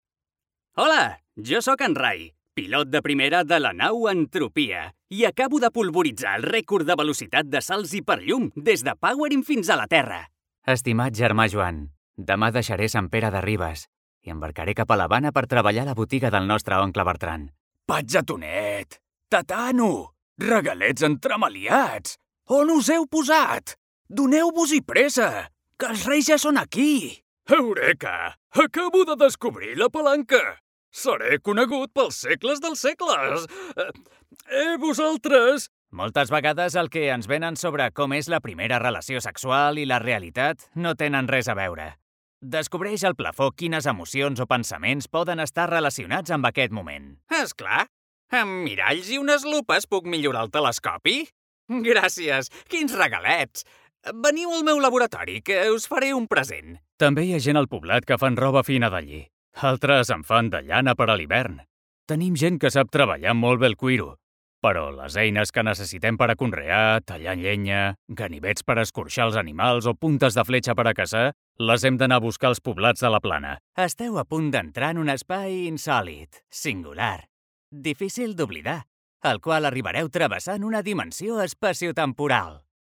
Jeune, Naturelle, Distinctive, Urbaine, Cool